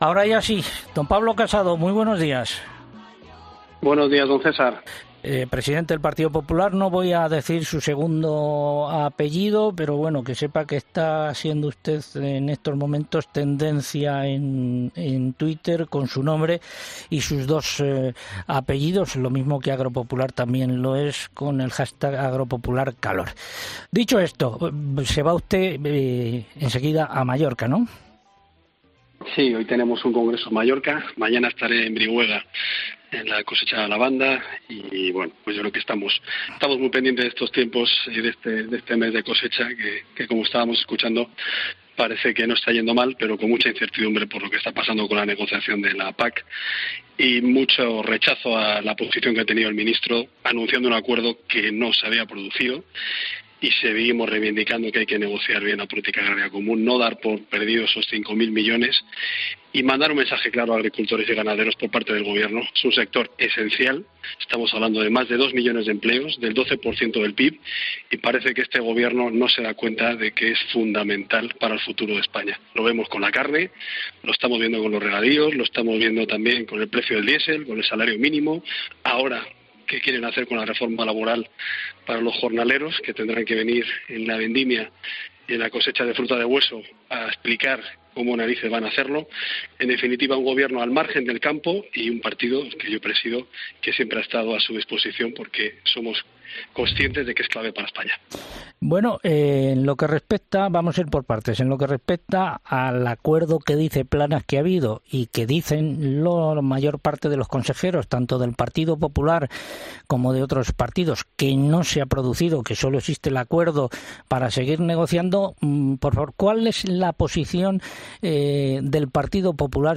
El presidente del PP ha mostrado su preocupación en COPE por la negociación de la PAC y apuesta por menos burocracia para combatir la despoblación